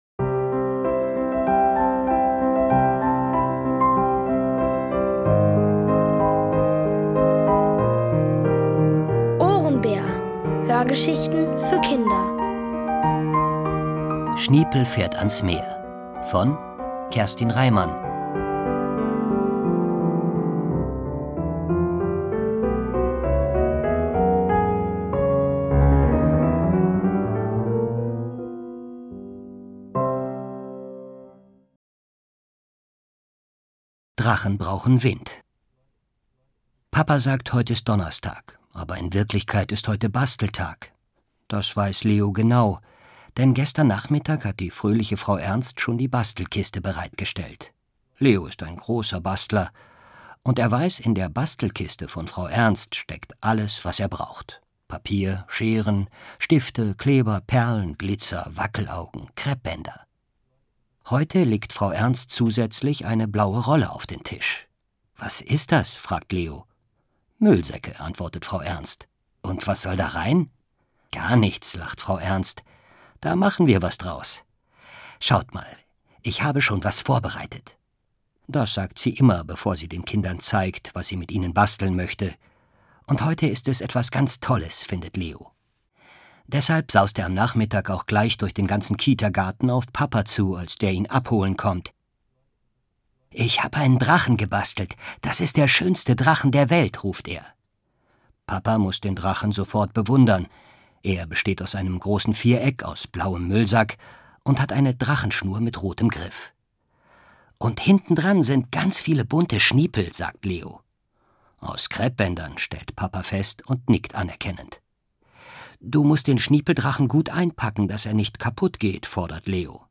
Von Autoren extra für die Reihe geschrieben und von bekannten Schauspielern gelesen.
Hörgeschichten empfohlen ab 4: